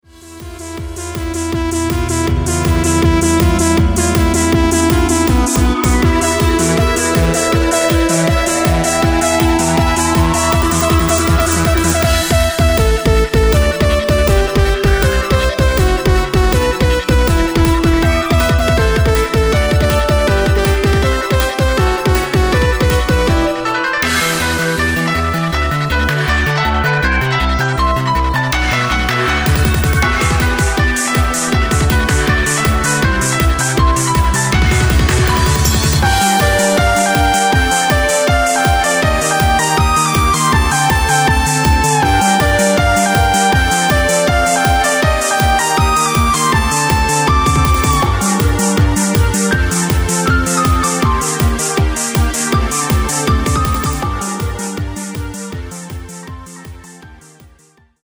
リッジの下地に旧作時代の東方のメロディを